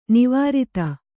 ♪ nivārita